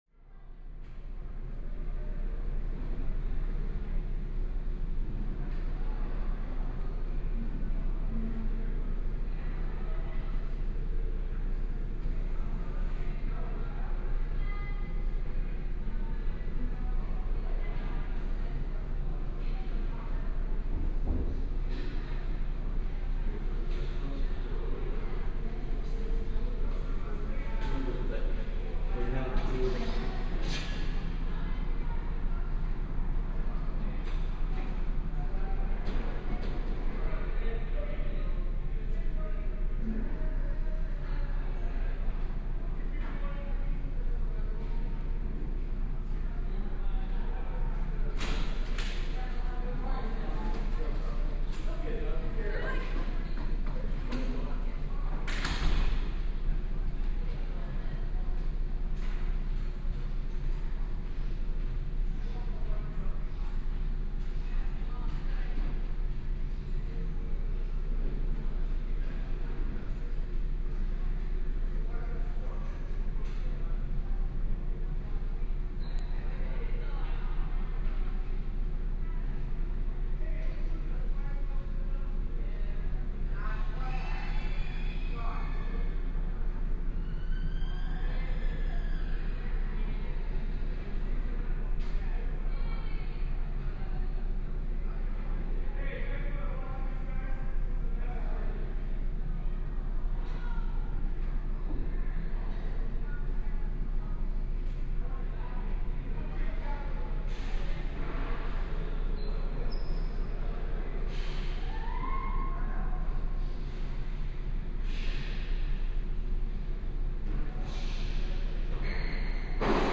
Звуки школьной перемены
Атмосферный звук школьного коридора